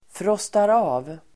Uttal: [fråstar'a:v]